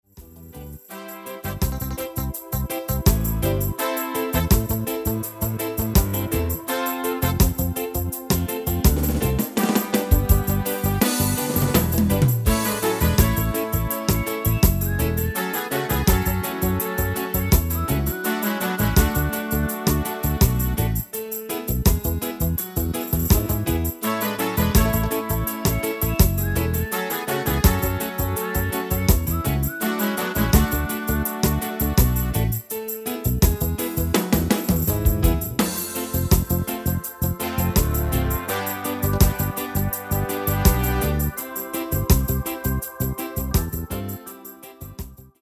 (Reggae version)
MP3 BackingTrack Euro 4.75
Demo's played are recordings from our digital arrangements.